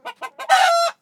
hen.ogg